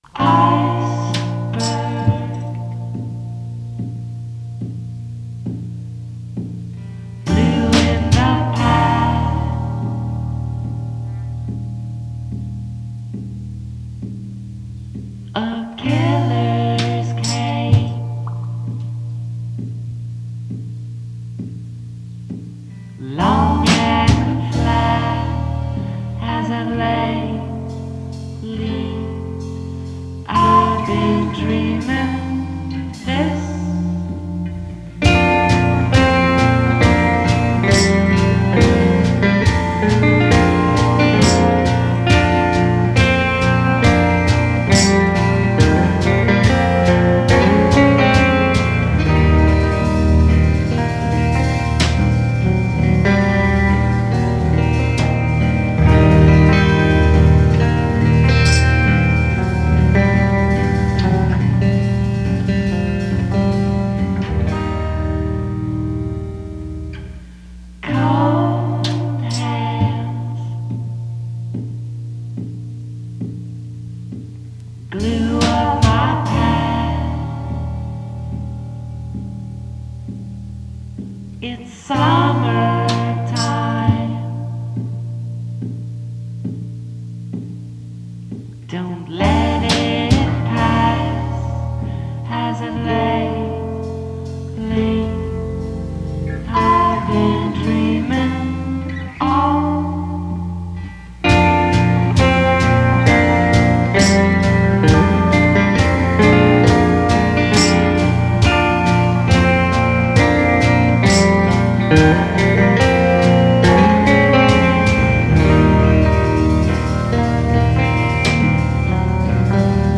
unyielding monolith in echo-chamber boy/girl whispers.